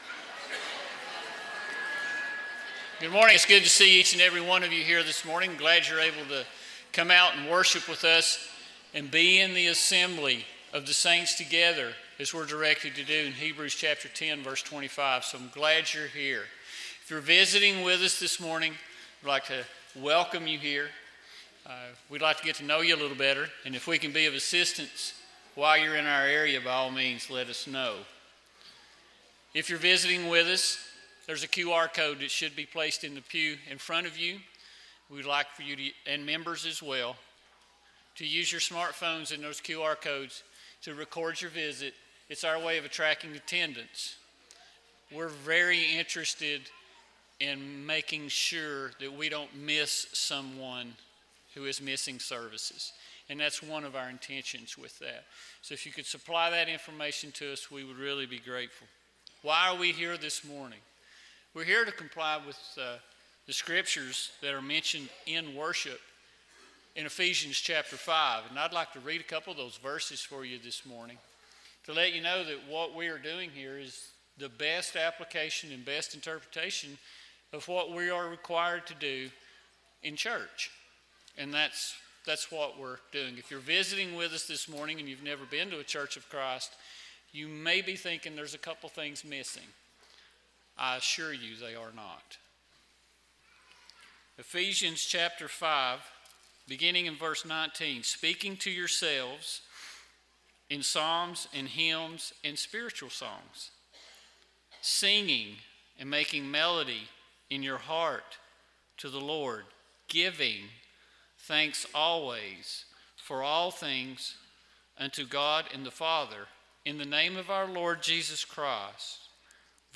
(1 Samuel 2:12, English Standard Version) Series: Sunday PM Service